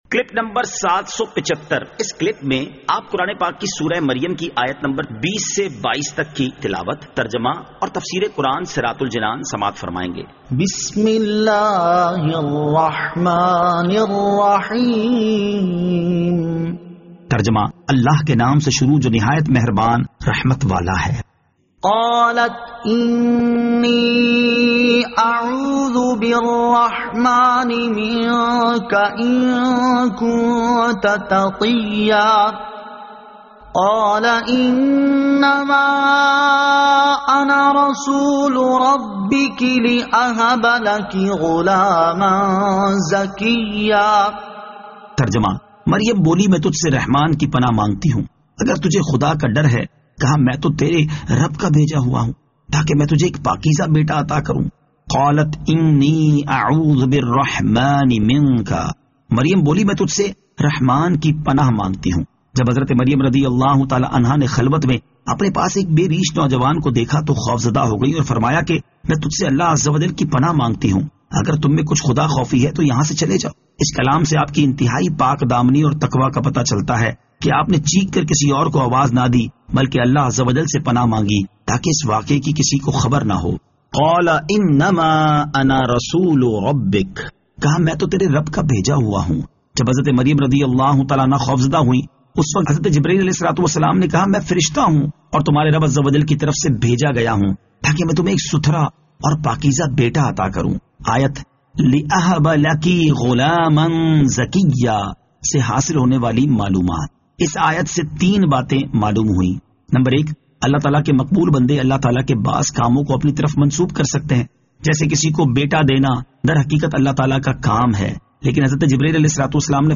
Surah Maryam Ayat 20 To 22 Tilawat , Tarjama , Tafseer